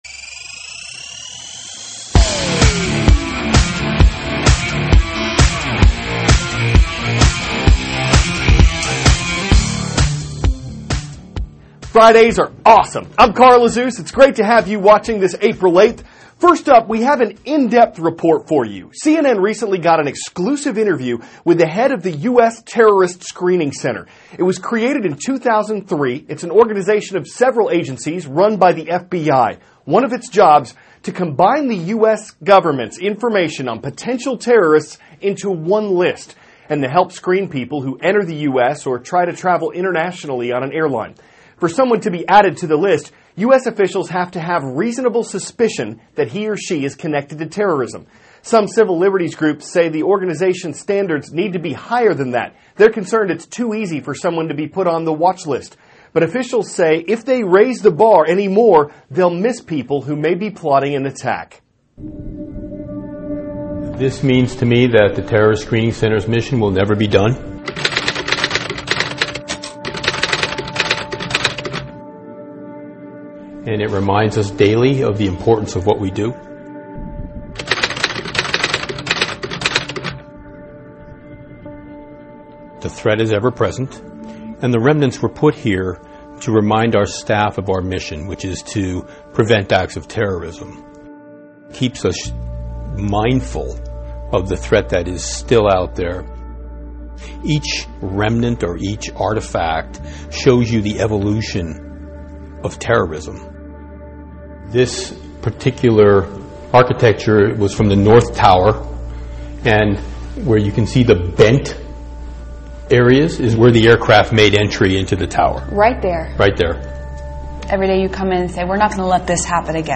CARL AZUZ, CNN STUDENT NEWS ANCHOR: Fridays are awesome!